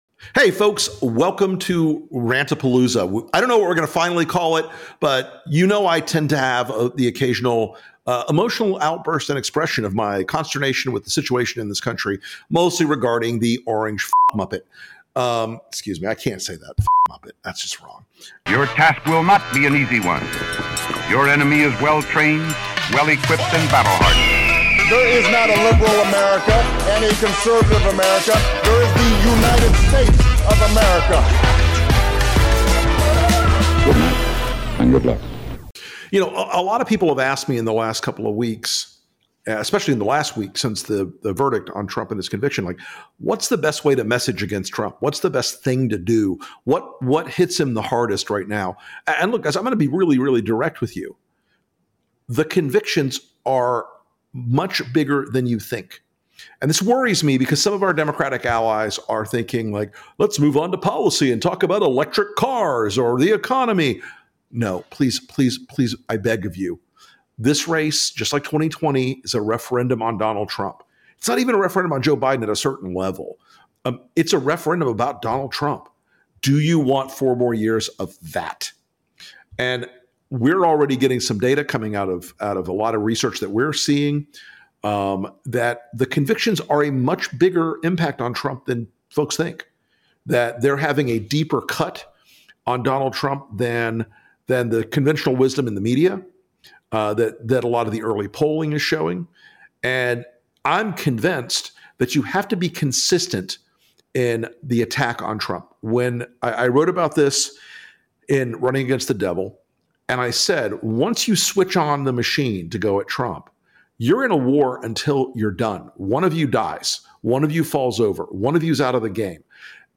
Host Rick Wilson has a come-to-Jesus moment with the campaigns and politicians who stand in opposition to Donald Trump. The lesson? Get on message.